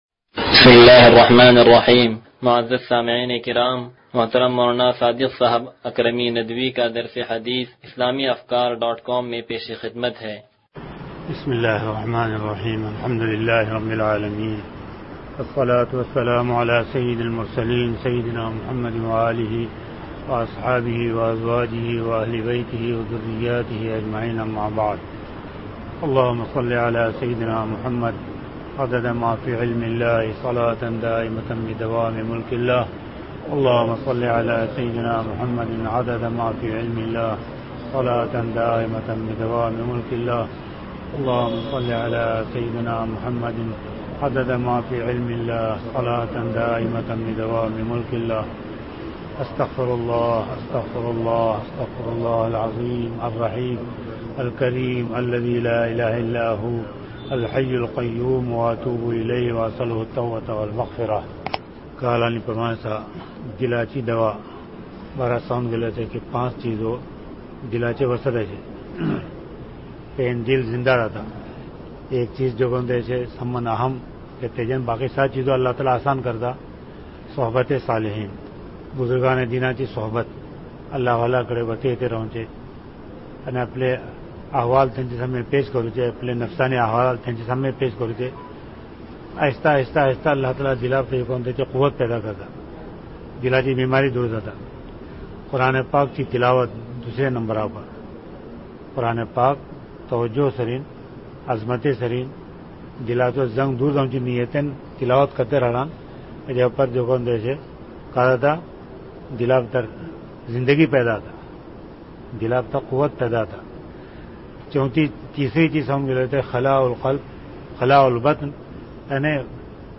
درس حدیث نمبر 0126